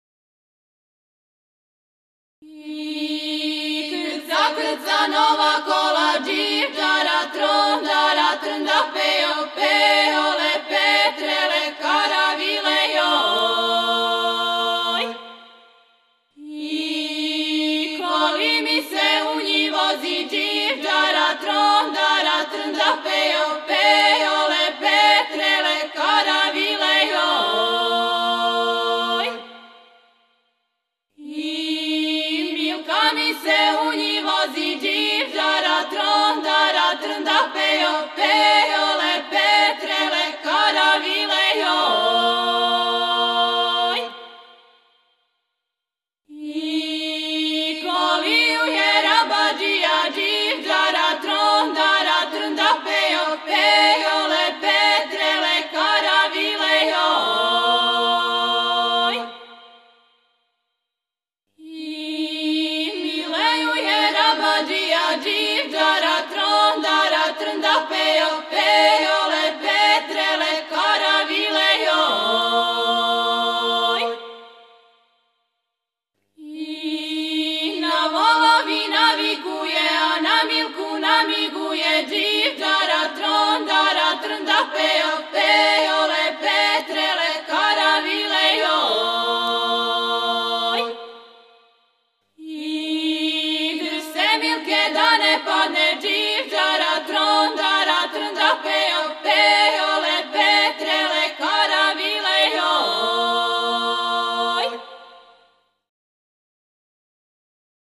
Порекло песме: Село Дуго Поље, Сокобања Начин певања: На бас. Напомена: Љубавна песма"